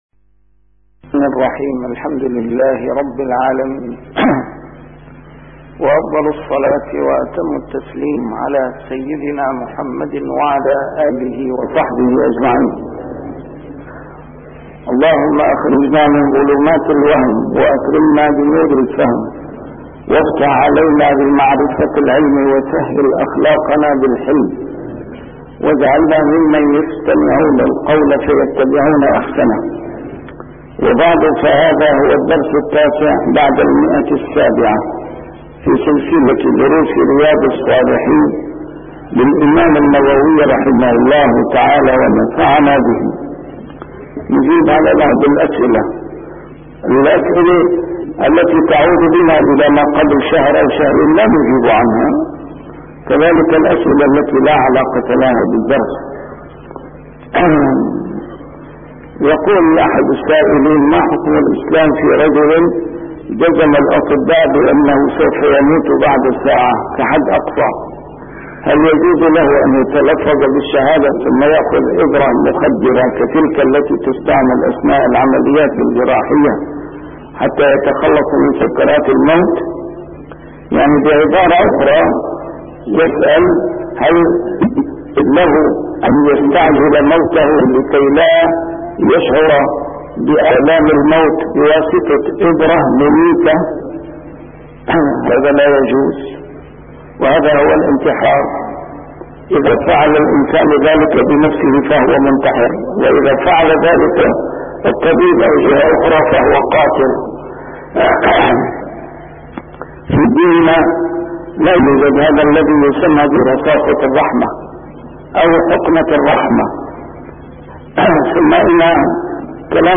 A MARTYR SCHOLAR: IMAM MUHAMMAD SAEED RAMADAN AL-BOUTI - الدروس العلمية - شرح كتاب رياض الصالحين - 709- شرح رياض الصالحين: ما يقوله بعد تغميض الميت